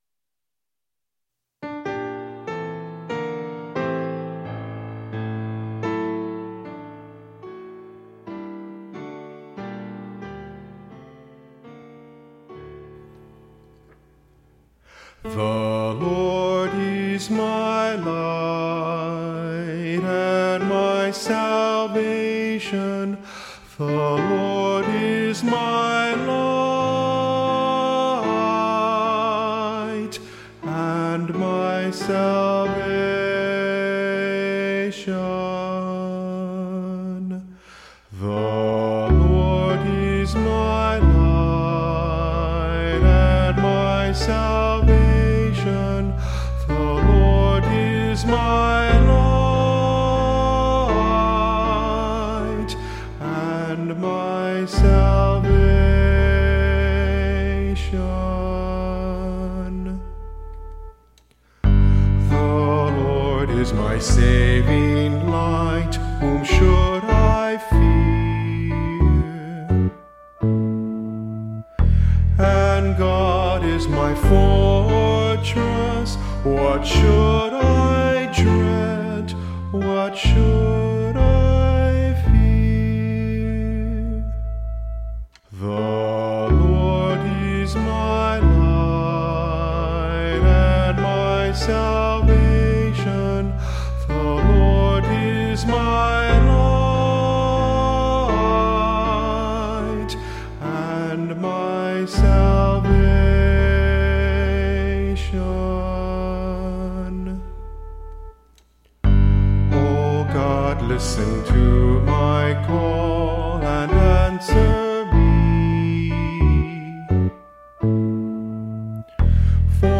Instrumental | Downloadable